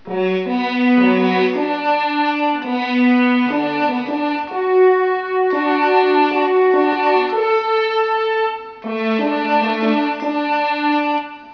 Now with a Roland GK kit